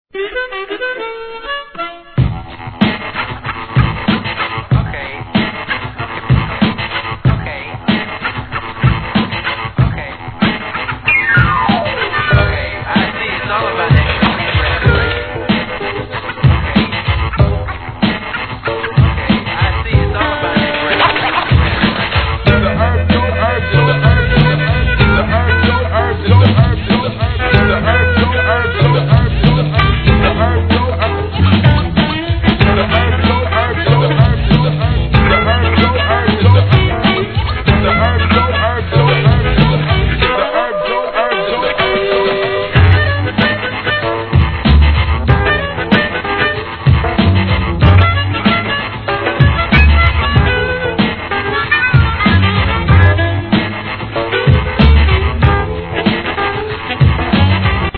ブルージーなハーモニカサンプルが印象的な1995年作シングル!